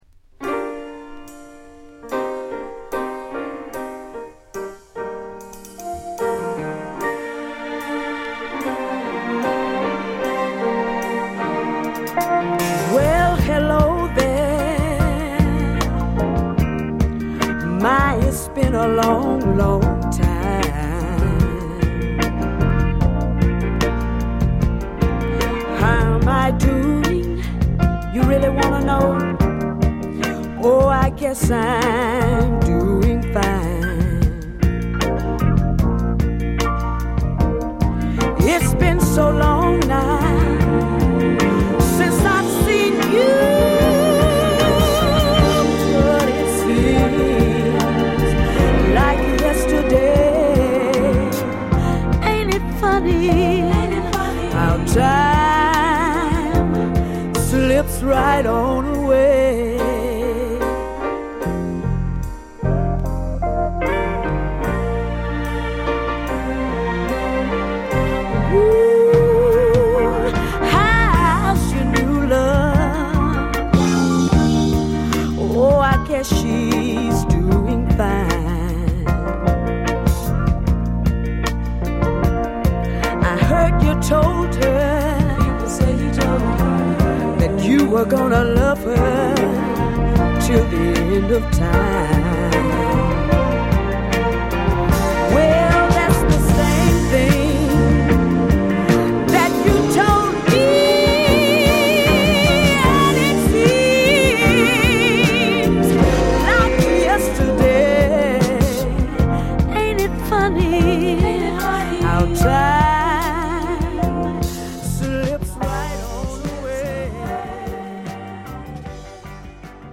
も、南部の雄大さとマイアミの軽やかさが同居した様な素晴らしい１曲